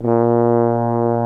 BRASS2-LOW.wav